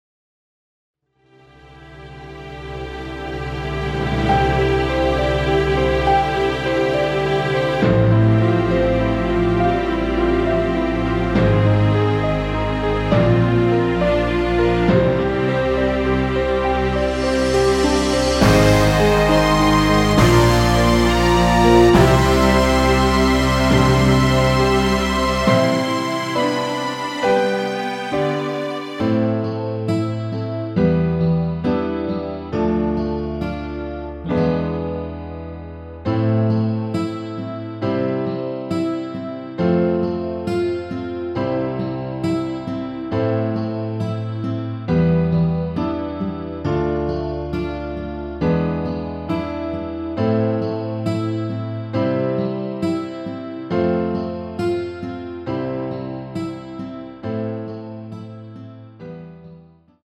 엔딩이 길고 페이드 아웃이라 짧게 엔딩을 만들어 놓았습니다.
Am
앞부분30초, 뒷부분30초씩 편집해서 올려 드리고 있습니다.